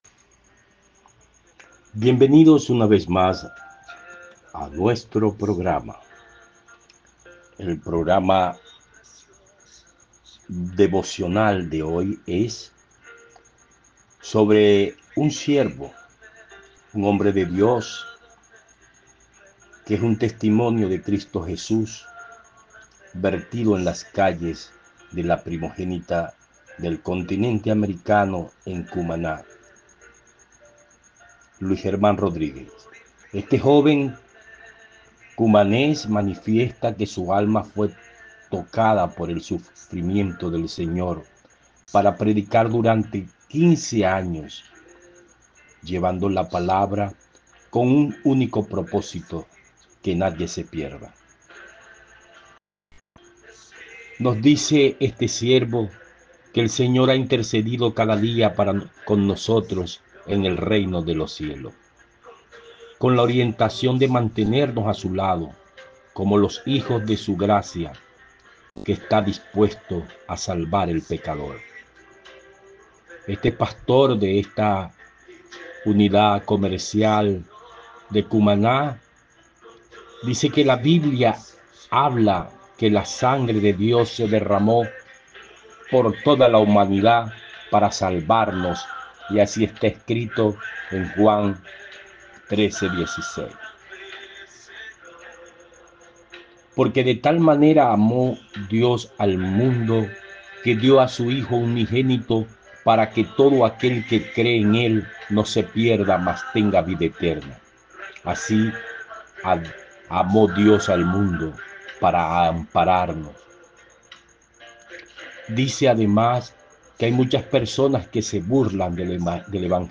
Mercado de Cumaná